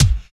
99 KICK 5.wav